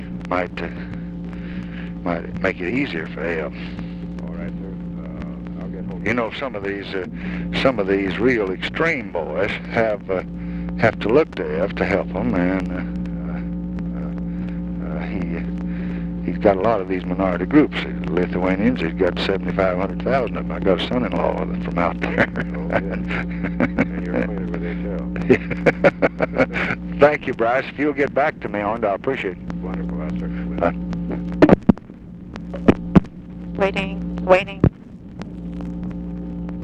Conversation with BRYCE HARLOW, February 2, 1967
Secret White House Tapes